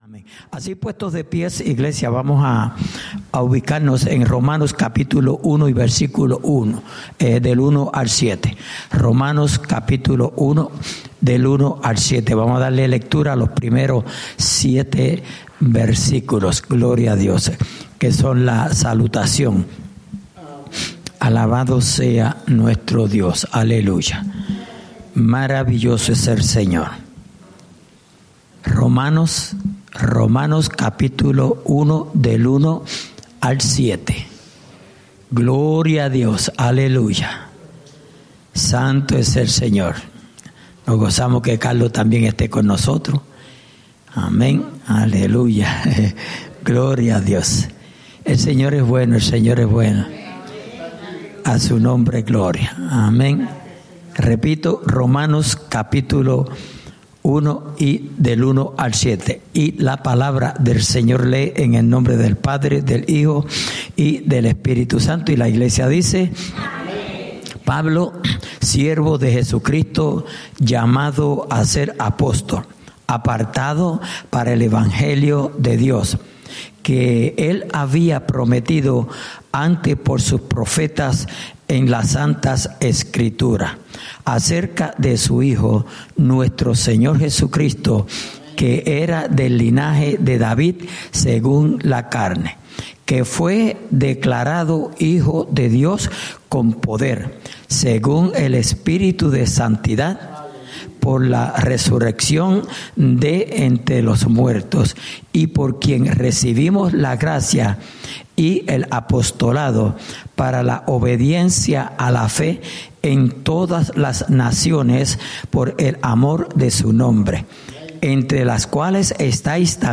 Estudio Bíblico: Libro de Romanos (Parte 4)